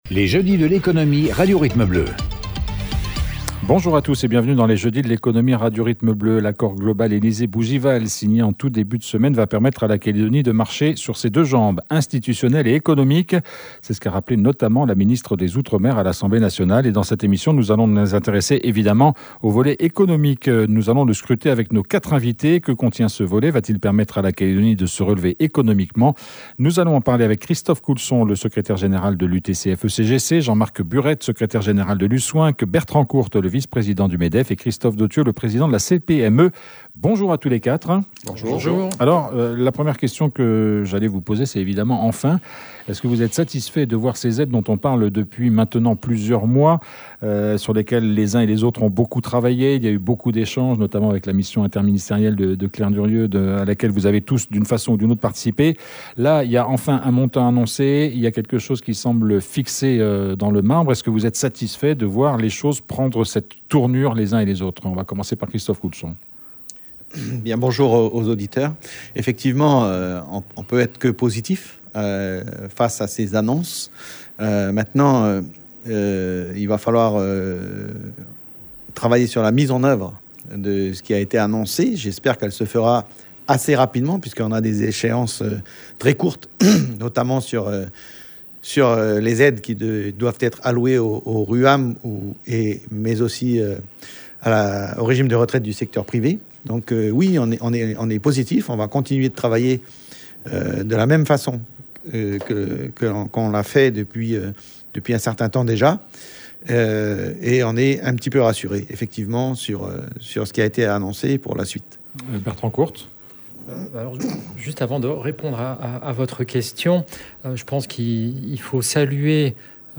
Va-t-il permettre à la Calédonie de se relever économiquement ? Quatre invités